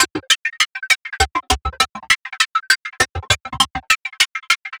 tx_synth_100_sharpfuzz_C1.wav